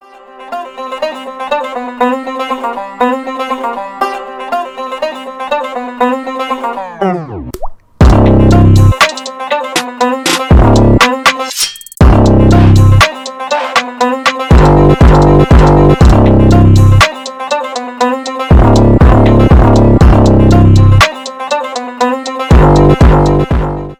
Рэп и Хип Хоп
без слов